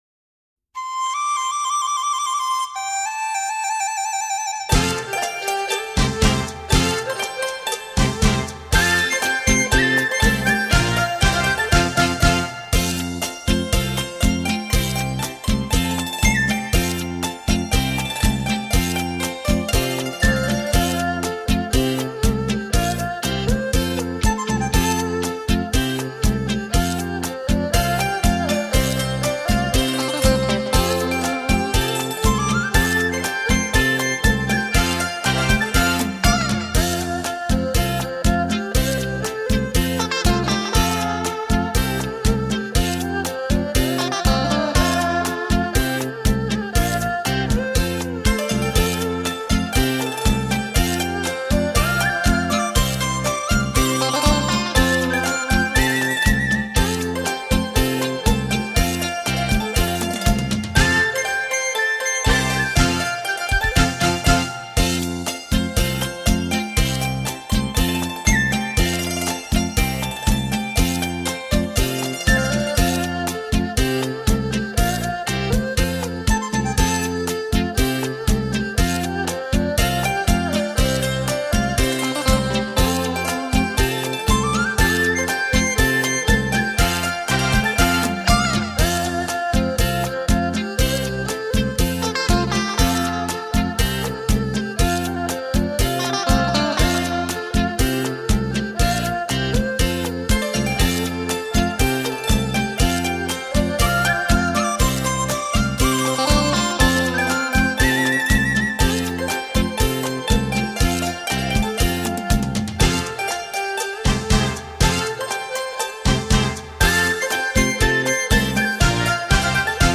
编曲演奏和新时代乐队很相似.